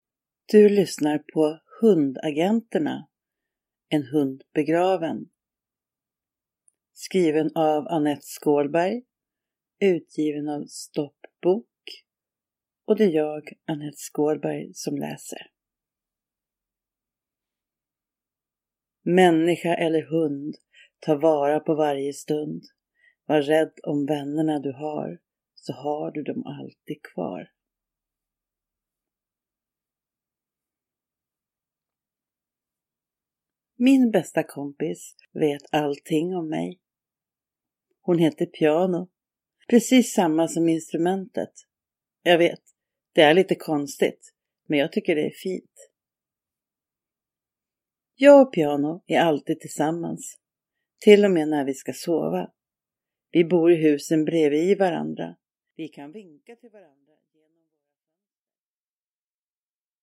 Hundagenterna EN HUND BEGRAVEN – Ljudbok
En lättläst och lättlyssnad historia med lite text och mycket handling.